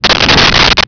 Sfx Whoosh 4704
sfx_whoosh_4704.wav